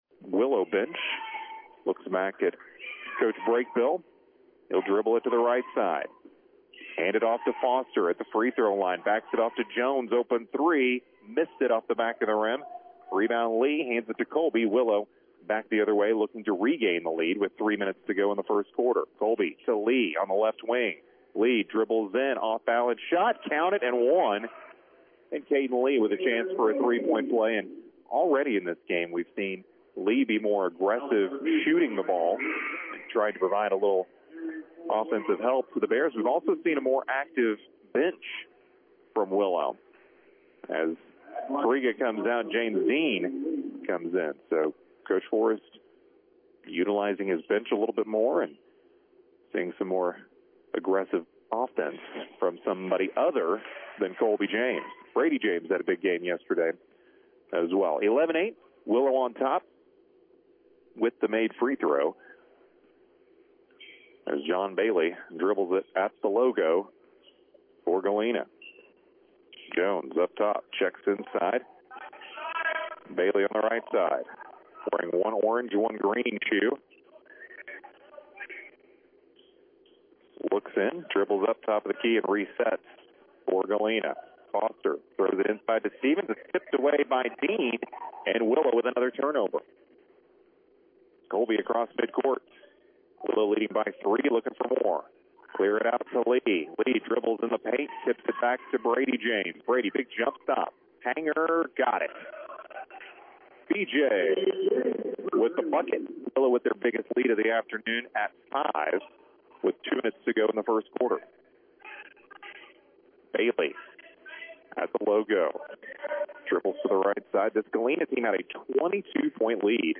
Game Audio Below: